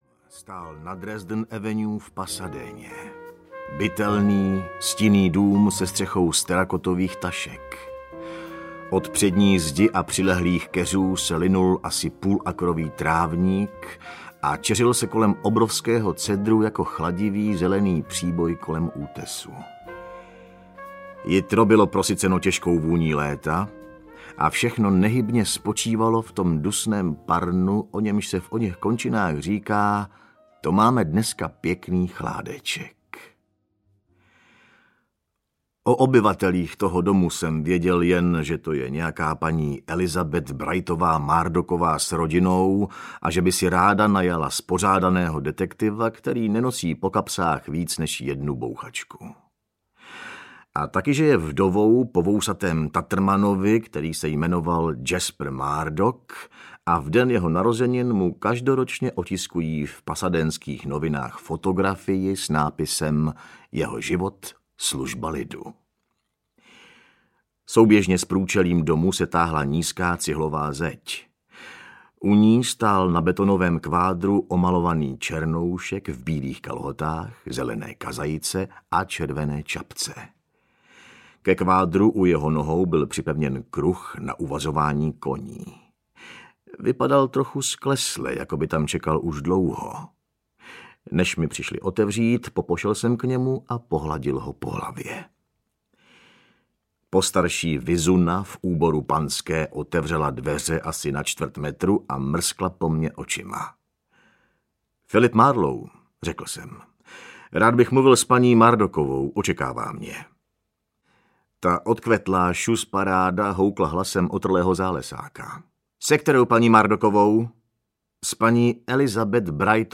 Vysoké okno audiokniha
Ukázka z knihy